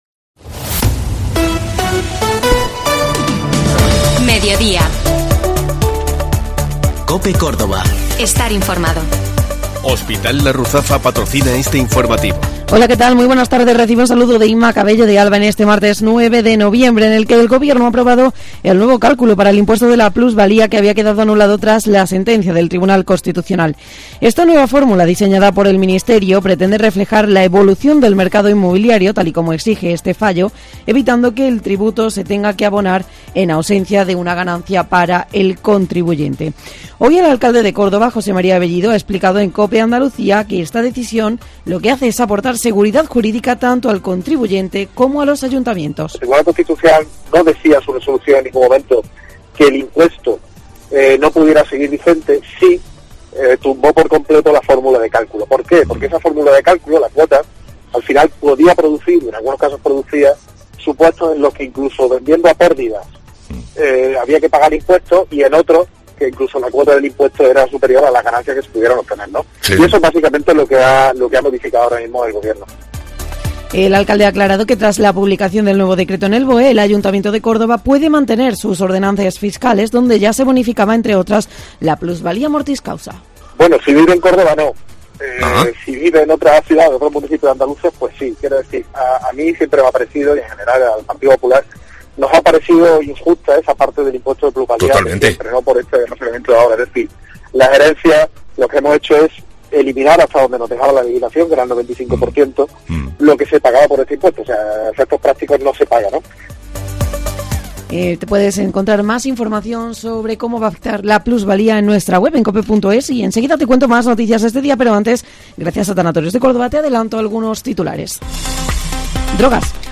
Informativo Mediodía COPE Córdoba
En este martes 9 de noviembre, analizamos junto al alcalde José Mª Bellido, las nuevas fórmulas de cálculo para la plusvalía.